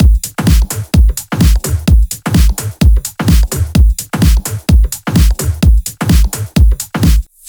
VFH3 128BPM Wobble House Kit
VFH3 128BPM Wobble House Kit 1.wav